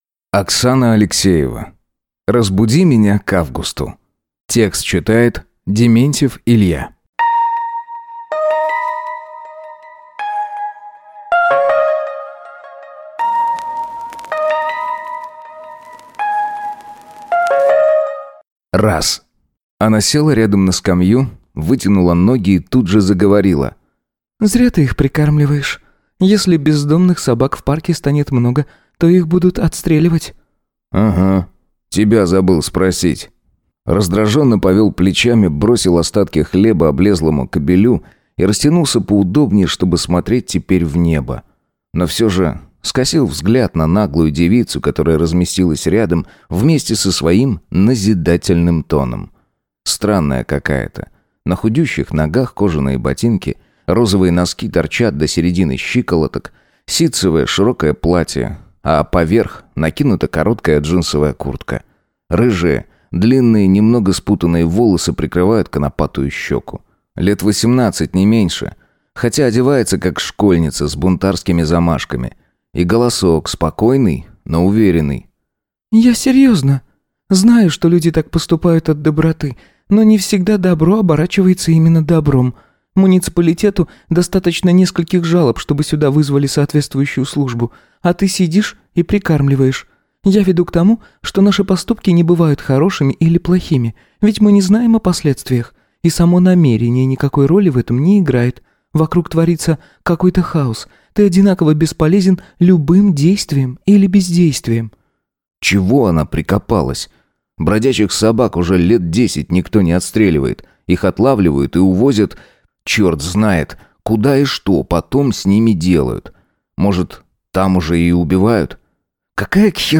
Аудиокнига Разбуди меня к августу | Библиотека аудиокниг